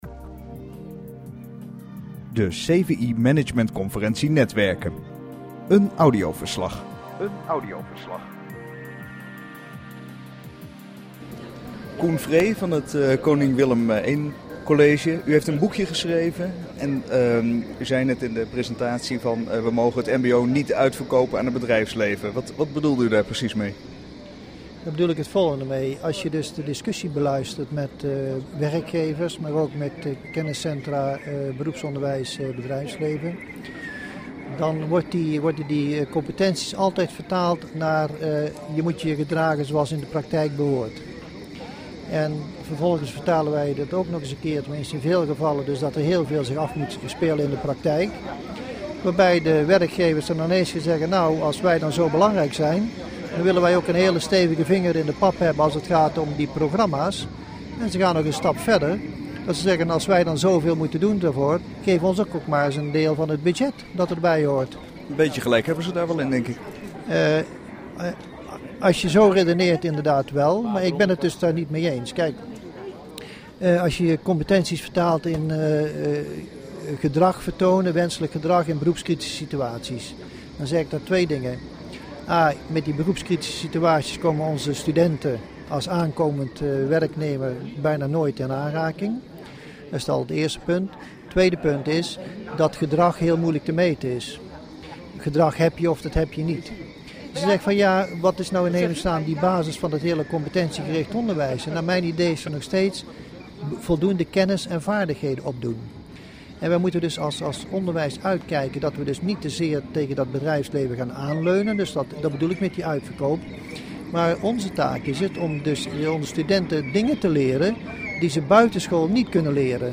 Ik had een kort gesprek met hem.